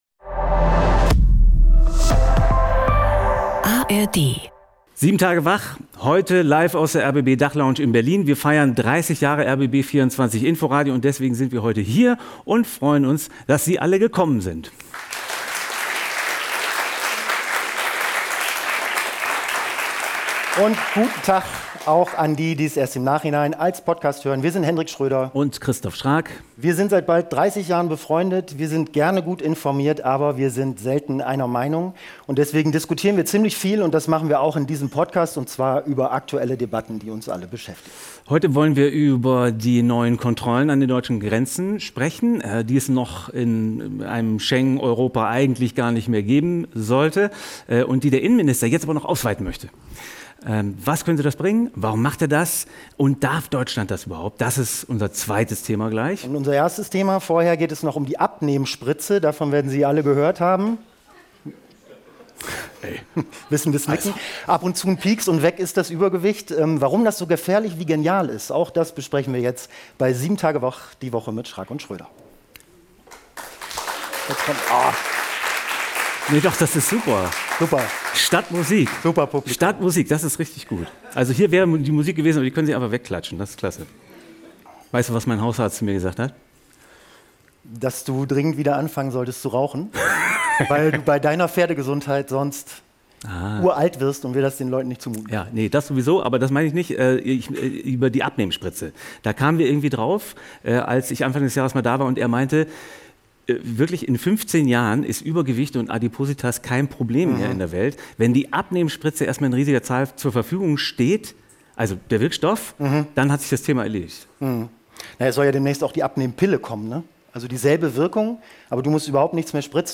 LIVE: Gewichtskontrolle! Einmal den Ausweis, bitte ~ 7 Tage wach Podcast